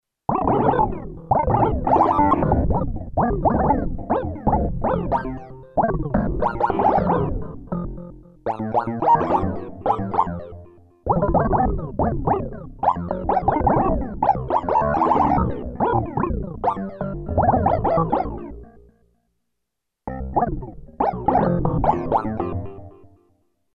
NPC Speech Sounds
Each NPC will have their own unique sounding voice pre-generated using synthesizers.
NOTE: These examples use heavy delay/echo which will not feature on the actual speech sounds.